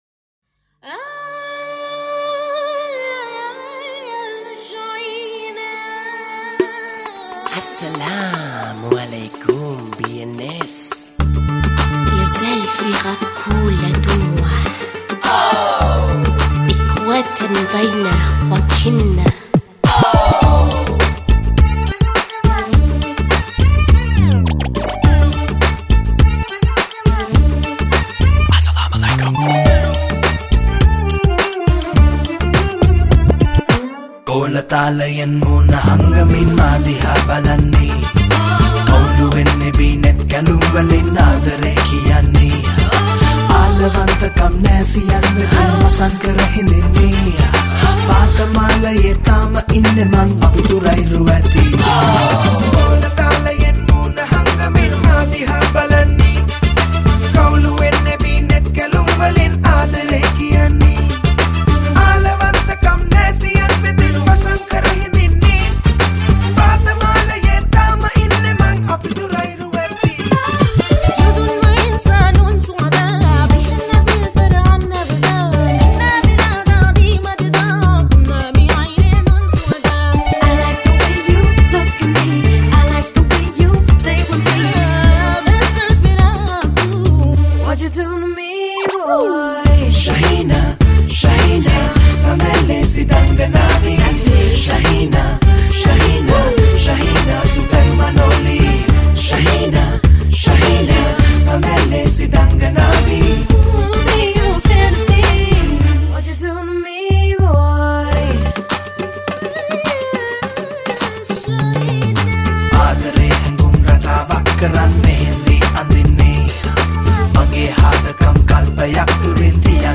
High quality Sri Lankan remix MP3 (3.6).